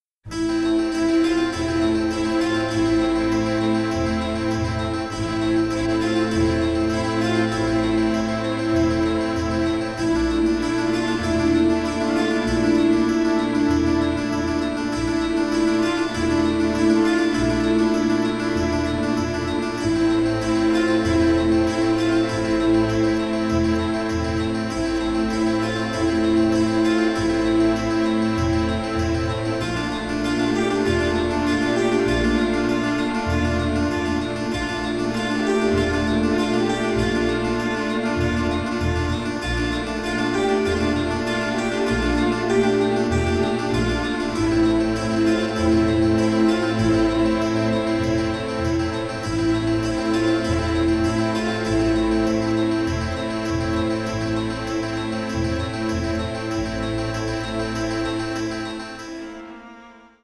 suspense writing for strings